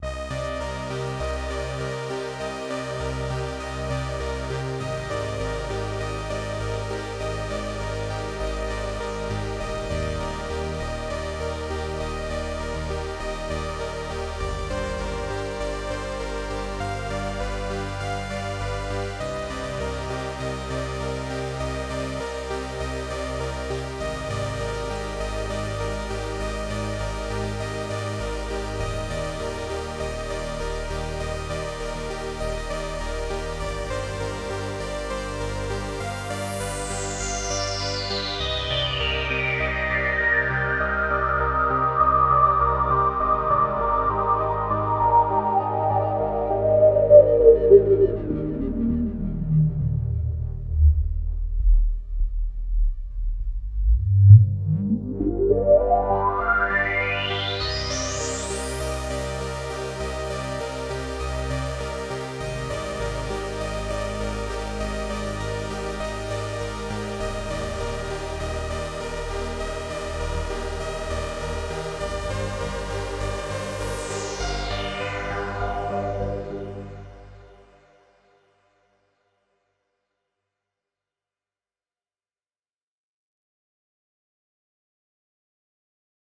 нуи так сказать чтоб уж совсем добить это восьмидесятое тельце, вот полифония с ТМТ 1-е 8 тактов без изменений 2-е 8 тактов растёт резонанс (обратите внимание на появление характерного для оберхаймов горба на ВЧ) 3-е 8 тактов катофф падает и растёт обратно (тут слышно и как он вибрирует, и как бас заводится на низких значениях) 4-е 8 тактов добавляем ФМ ЗЫ: пришлось делать громкость на большей части дорожки тише, чтоб резонанс не клиппил - настолько он там разворачивается в НЧ области, уххх Вложения MiamiHausen-Komplete Kontrol 1 (Bounced).mp3 MiamiHausen-Komplete Kontrol 1 (Bounced).mp3 3,3 MB · Просмотры: 2.141